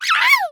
Hit Small Creature.wav